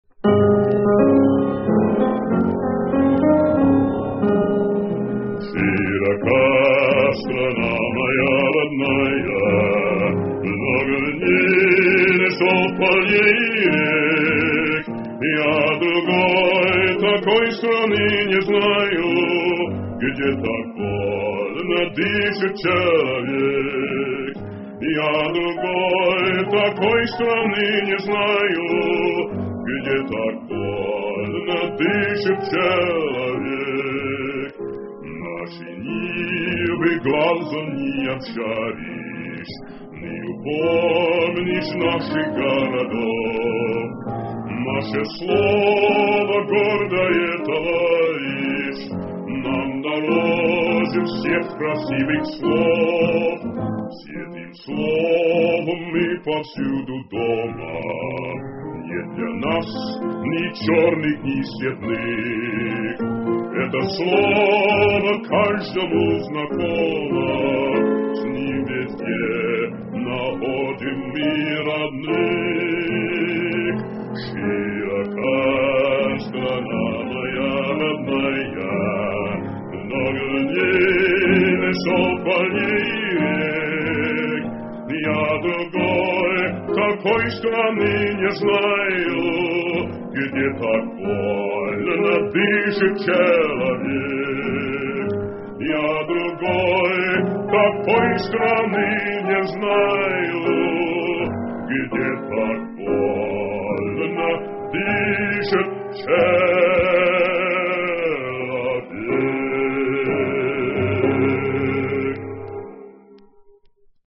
густым и бархатным басом